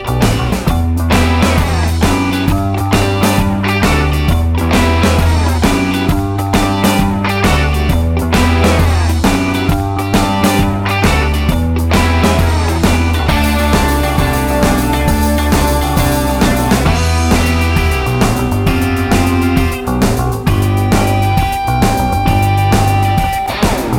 no Backing Vocals Punk 3:21 Buy £1.50